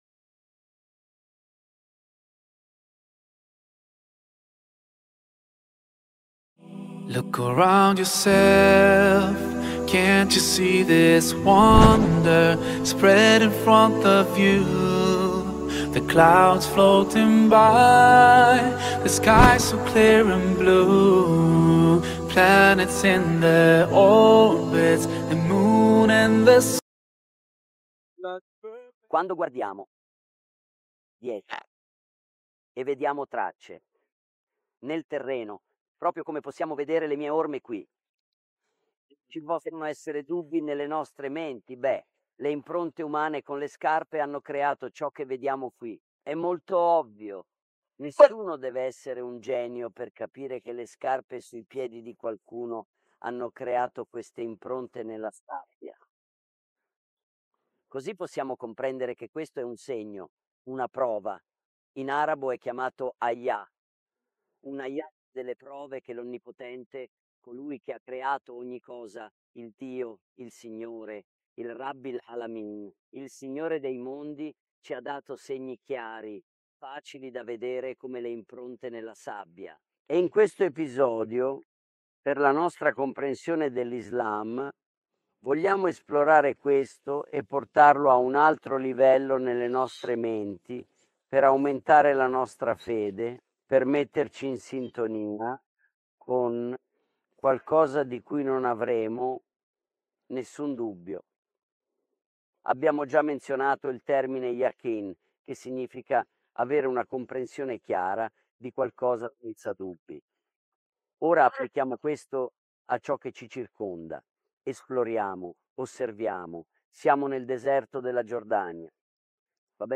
filmed in the backdrop of scenic landscapes and historic places of Jordan. In this episode, he explains the signs of God in the creation, and that we have a greater purpose.